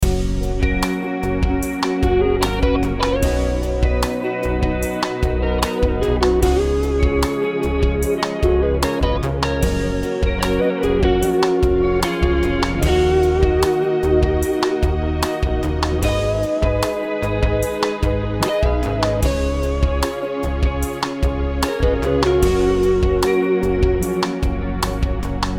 Music Ringtones